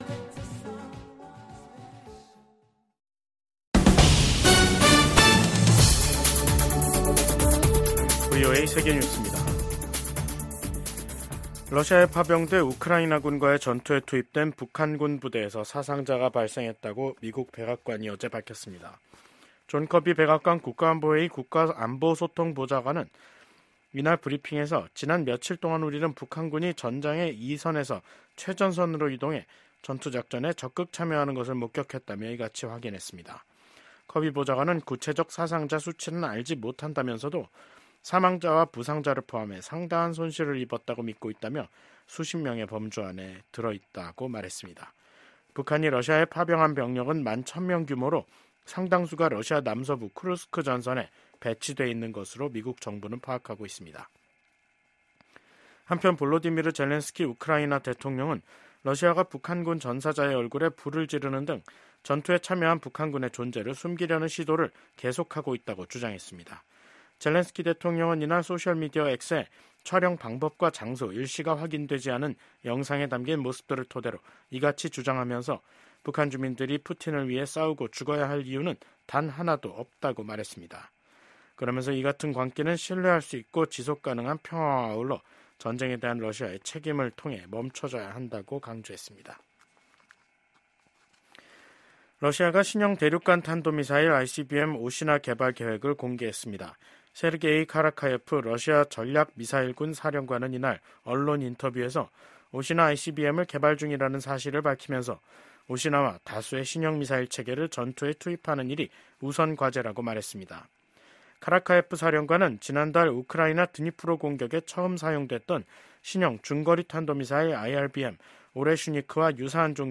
VOA 한국어 간판 뉴스 프로그램 '뉴스 투데이', 2024년 12월 17일 3부 방송입니다. 윤석열 한국 대통령이 탄핵소추안 가결로 직무가 정지되면서 윤석열 정부가 추진해 온 강경기조의 대북정책 동력도 약화될 것이라는 전망이 나옵니다. 미국 정부는 윤석열 대통령 탄핵소추안이 한국 국회에서 가결된 것과 관련해 한국의 민주주의와 법치주의에 대한 지지 입장을 재확인했습니다.